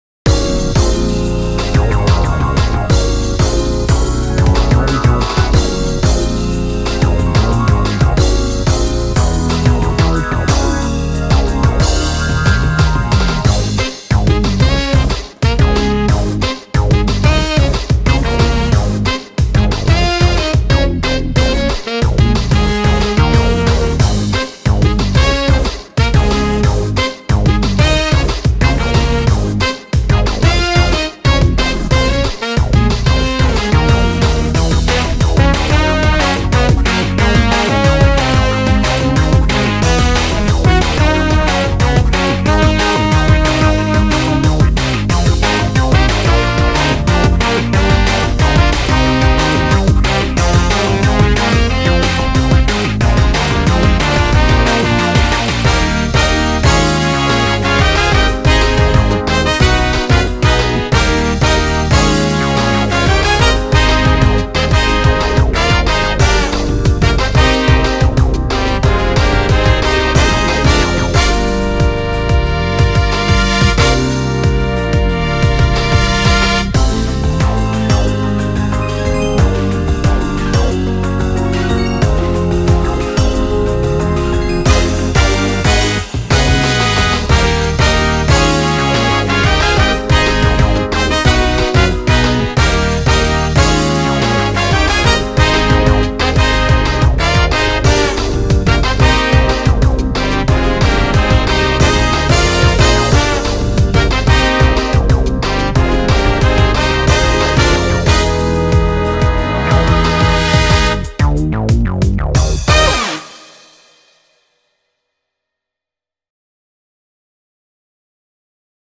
BPM182
Audio QualityCut From Video